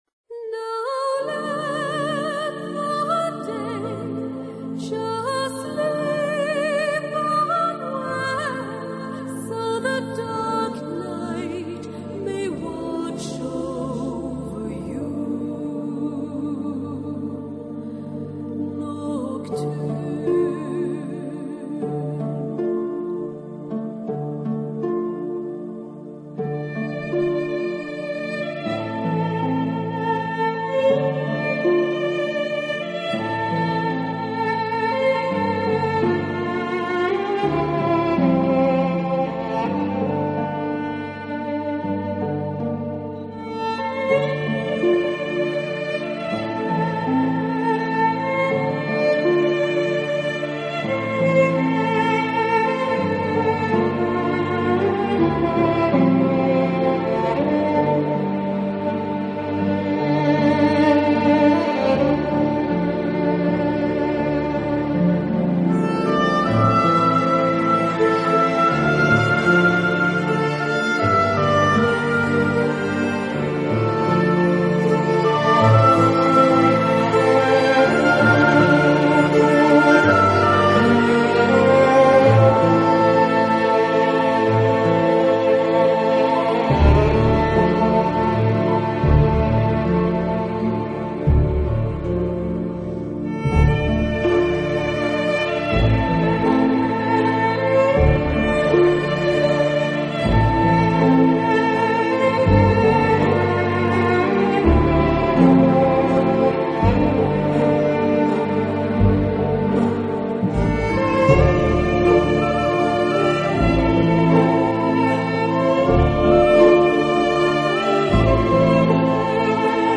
【新世纪音乐】
乐队融合了爱尔兰空灵飘渺的乐风以及挪威民族音乐及古典音乐，乐曲恬静深远，自然
流畅，使人不知不觉便已融入其中。